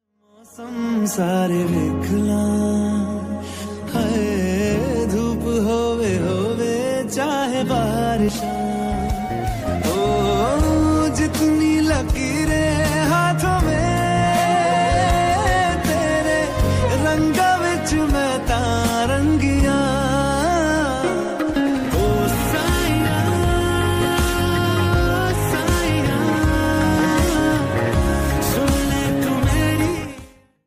Category: Hindi Ringtones